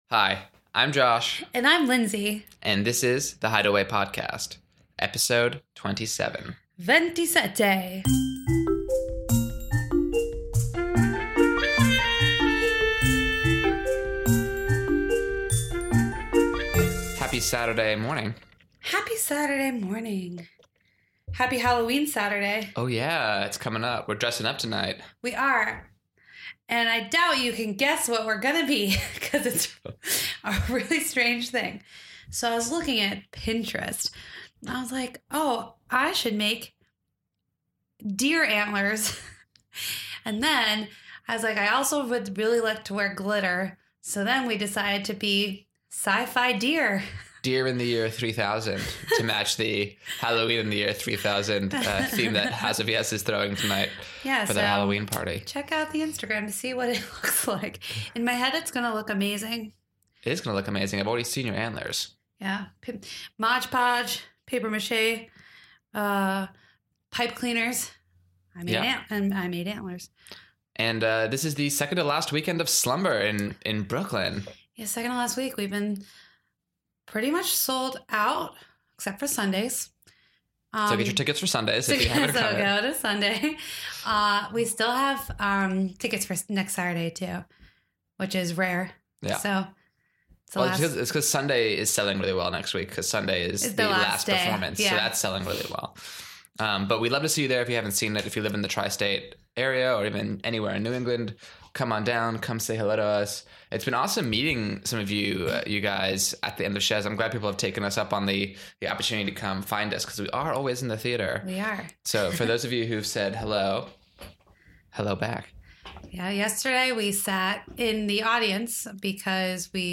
Episode 27- Happy Halloween and an interview